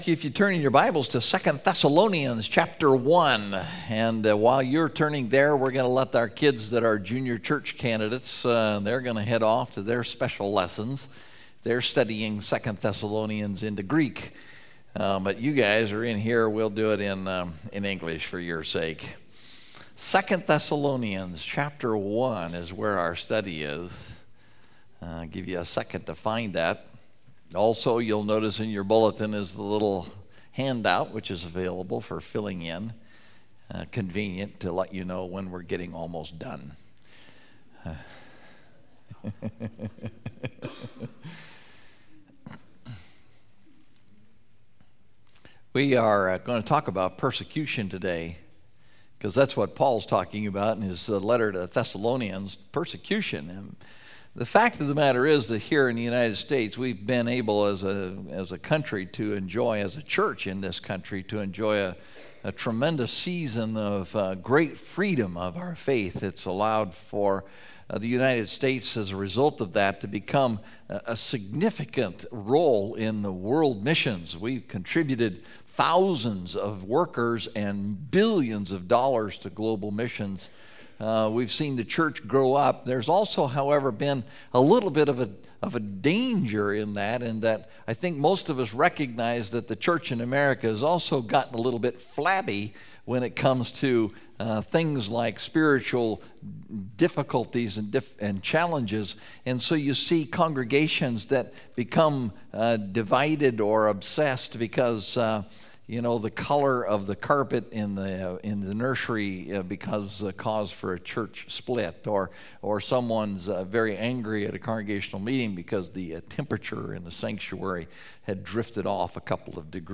The Biblical View of Persecution (2 Thessalonians 1:5-12) – Mountain View Baptist Church